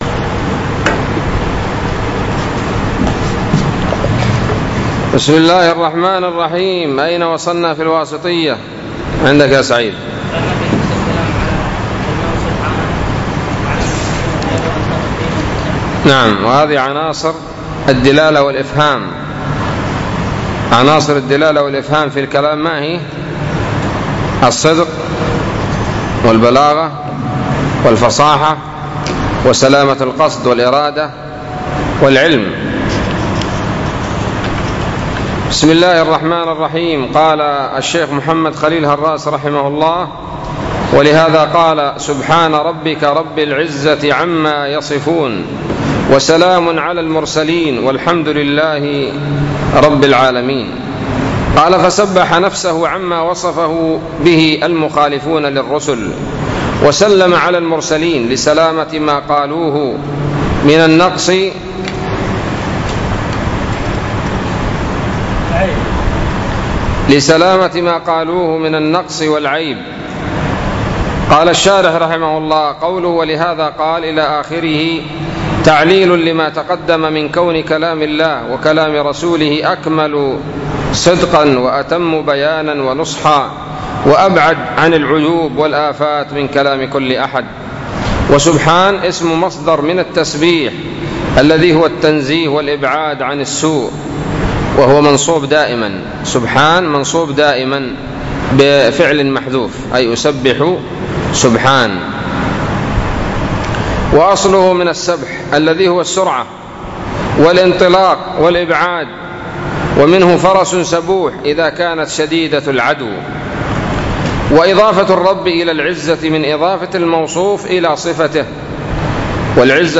الدرس الثامن والعشرون من شرح العقيدة الواسطية